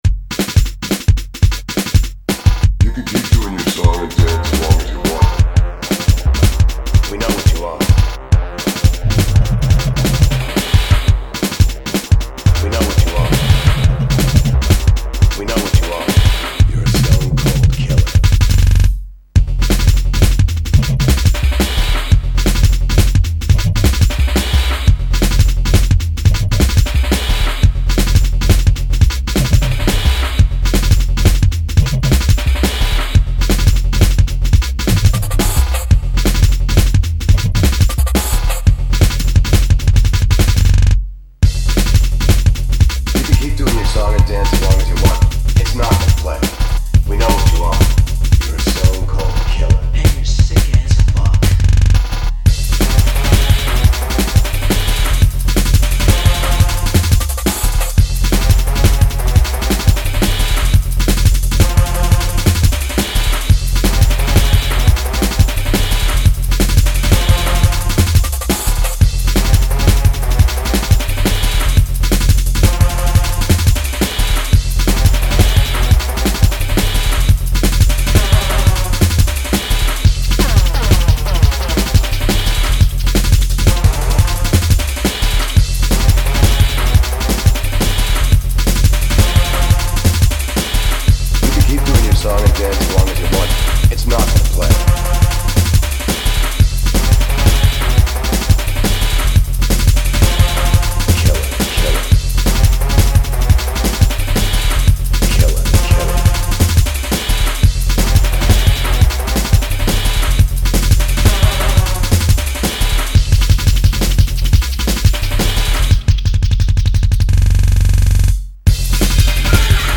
dance/electronic
Drum & bass